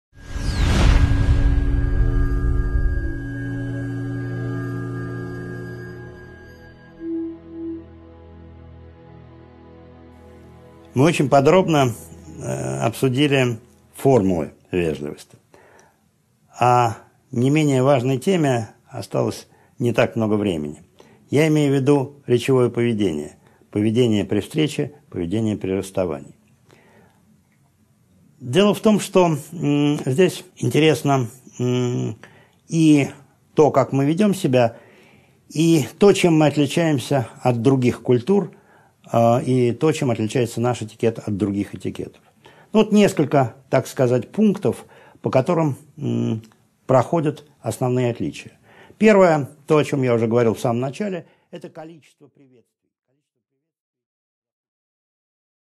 Аудиокнига 3.7 Межкультурное несоответствие речевого поведения | Библиотека аудиокниг